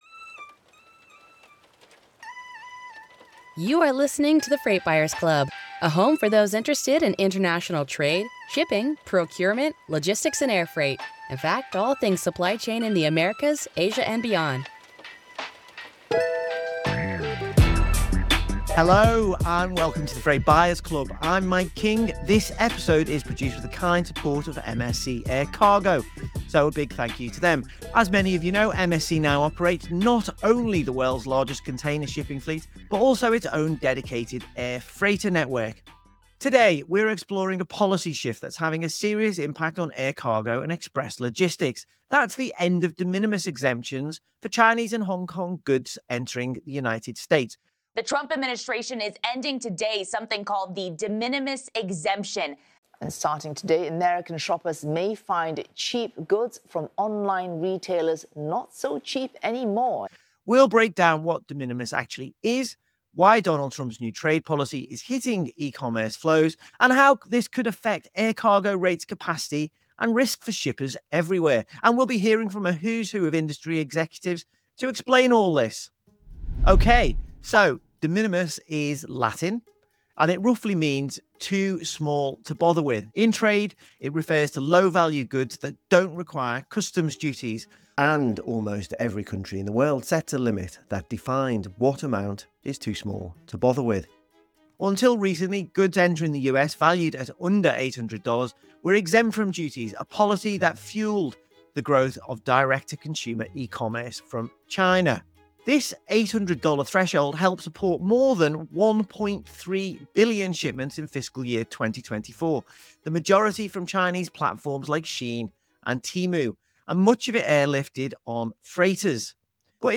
Explainer: Understanding De Minimis Reform and Its Impact on Air Cargo (Video)